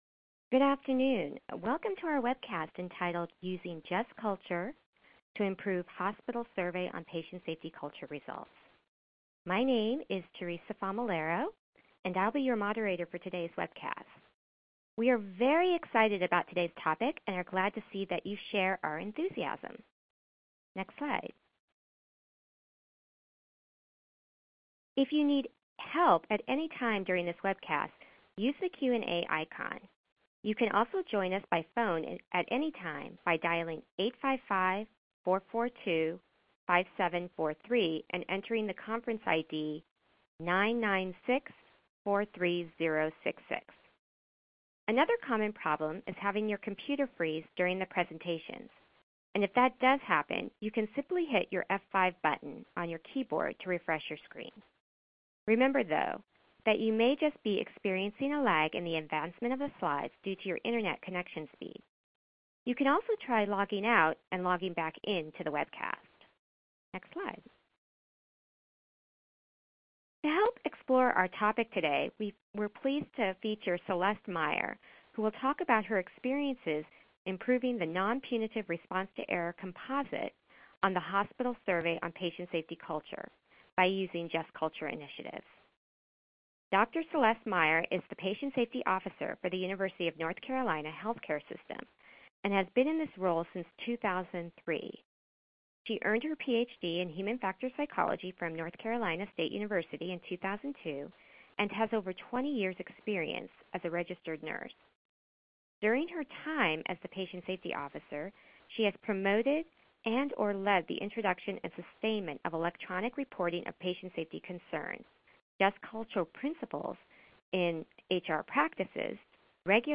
On November 9, 2016, the Agency for Healthcare Research and Quality (AHRQ) hosted a webcast featuring Just Culture initiatives that helped improve scores on the Hospital Survey on Patient Safety Culture, particularly the Nonpunitive Response to Error composite. Topics discussed during the webcast included strategies used to implement Just Culture in a health care system and how these led to improved results on the survey.